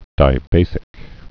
(dī-bāsĭk)